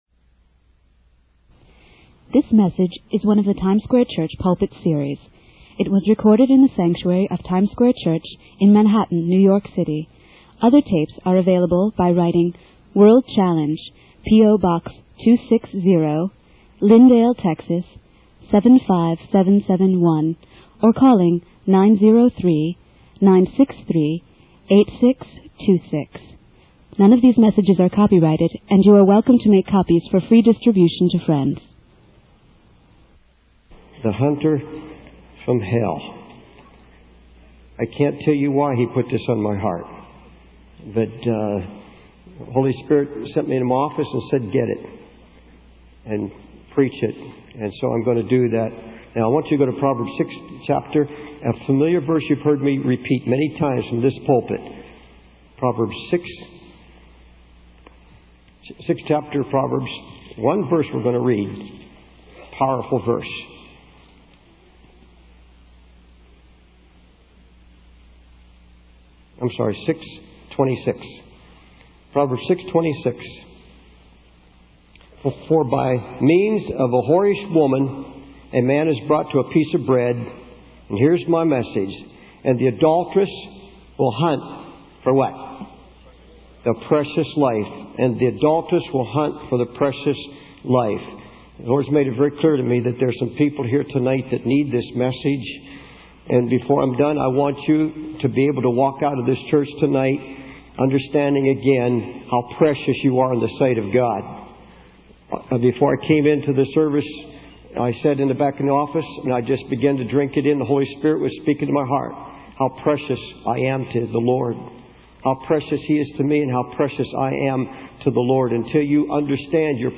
In this sermon, the preacher shares a personal story about a desperate teenage boy he encountered in Brooklyn.